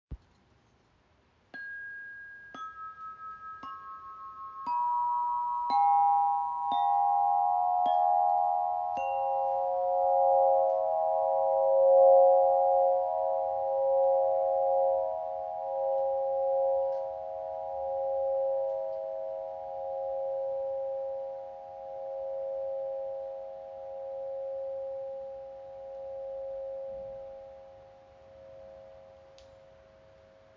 Klangbeispiel
Spiele die Chimes mit dem mitgelieferten Schlägel und schwinge sie herum, um einen sphärischen, beruhigenden Klang zu erzeugen.
Die Röhren sind aus korrosionsbeständigem Aluminium gefertigt und können einzeln oder zusammen gespielt werden.
Stimmung C# Minor in 432 Hz: A4, C#5, E5, F#5, G#5, B5, C#6, E6, G#6